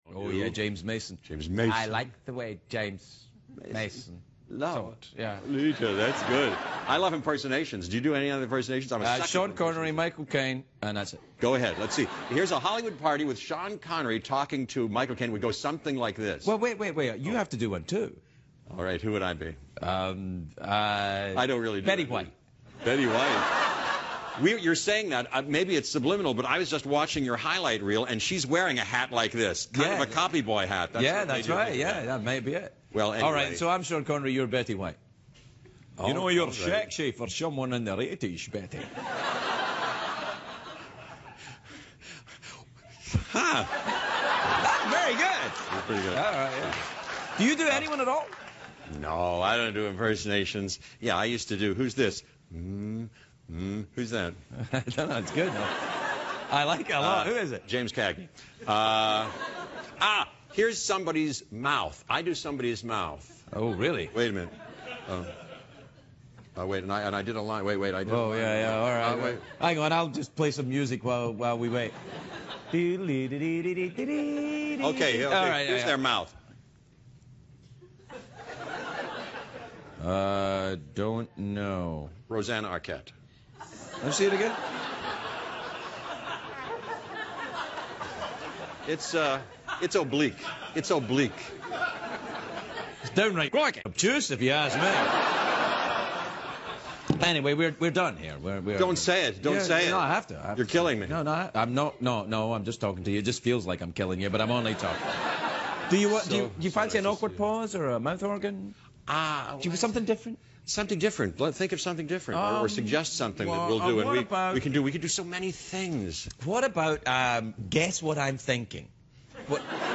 访谈录 2011-05-16&05-18 杰夫·高布伦专访 听力文件下载—在线英语听力室